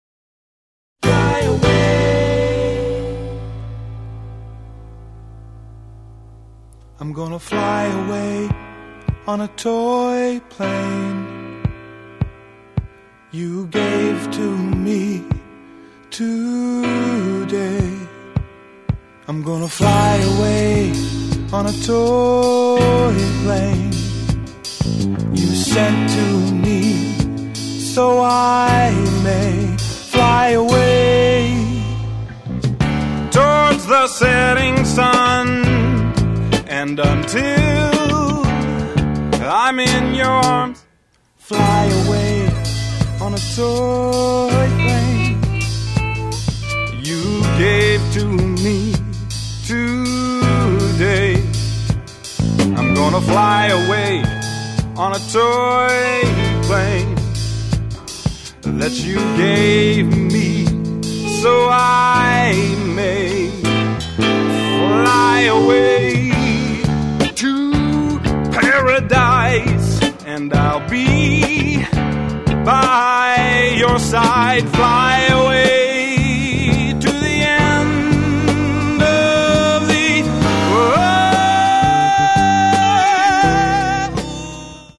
Soul, Funk, Jazz, Brazilian, and Latin music